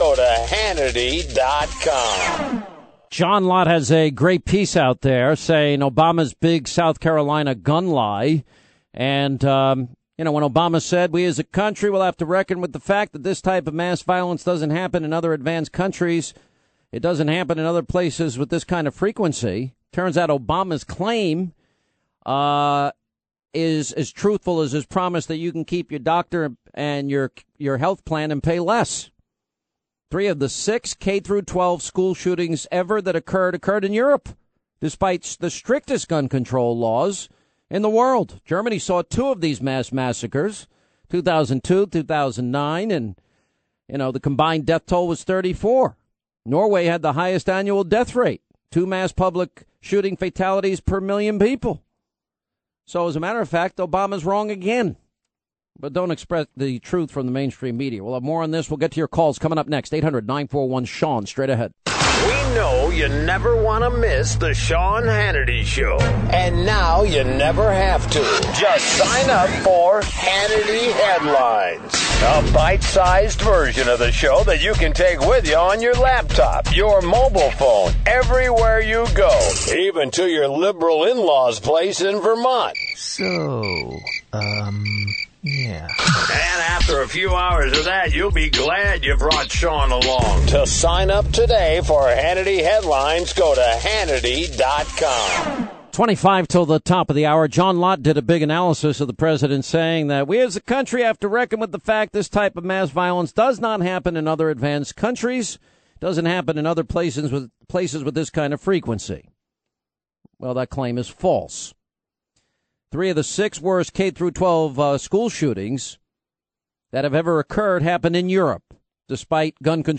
Sean Hannity reads CPRC op-ed on “The Myth of American Gun Violence” on his National Radio Show
John Lott’s op-ed titled “The Myth of American Gun Violence” at the New York Daily News was read by Sean Hannity on his national radio show (audience 12.5 million).